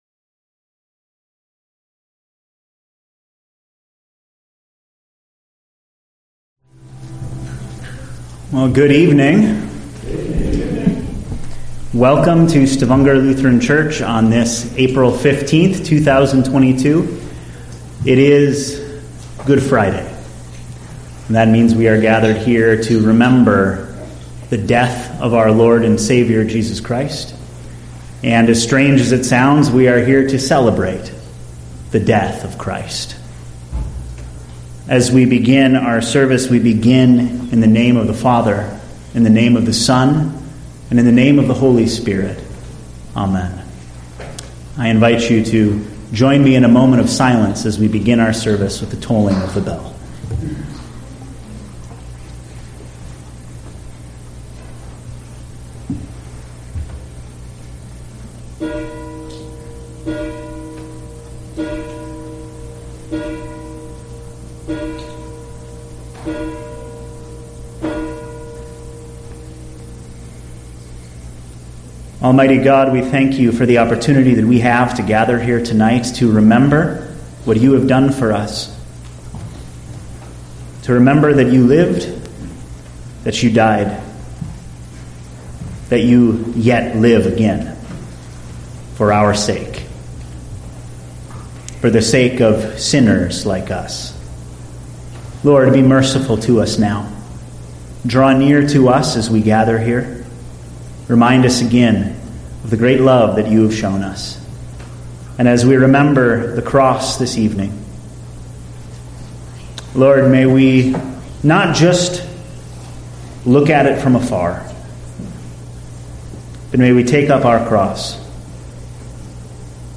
A message from the series "Sunday Worship." Sunday Worship - Soil Management - Matthew 13:1-9